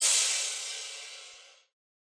Crashes & Cymbals